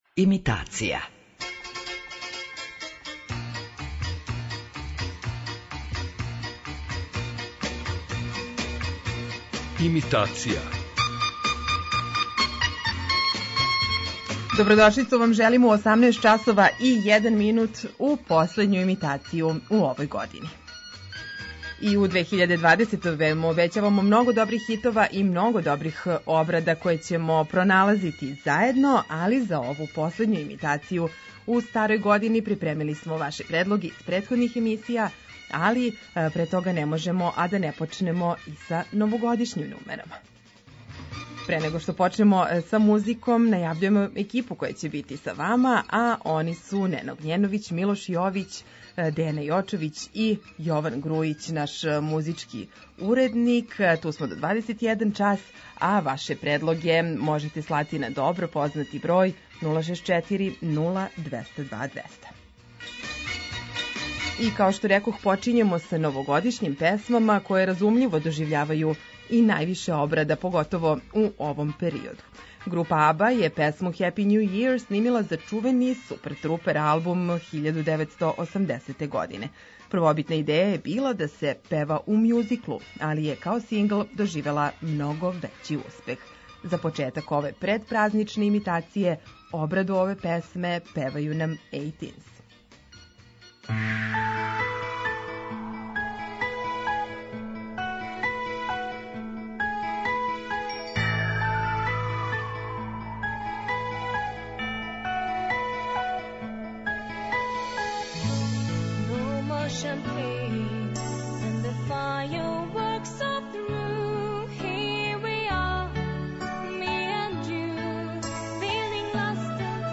Имитација је емисија у којој емитујeмо обраде познатих хитова домаће и иностране музике.